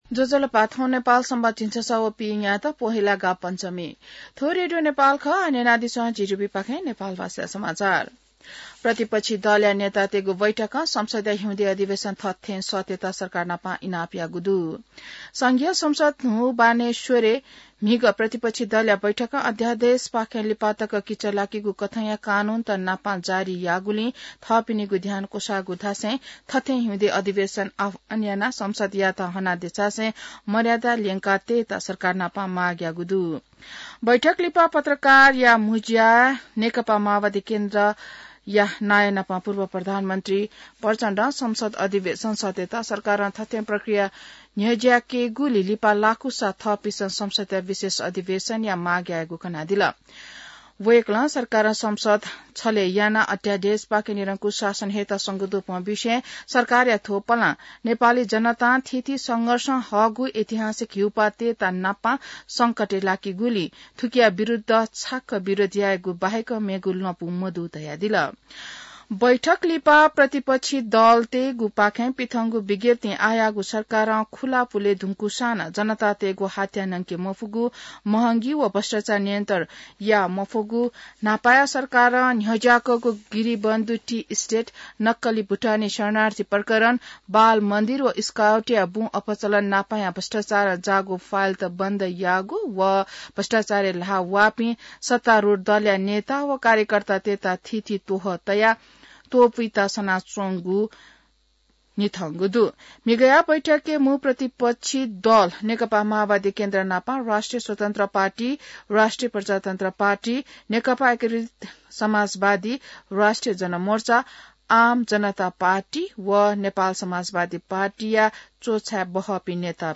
नेपाल भाषामा समाचार : ६ माघ , २०८१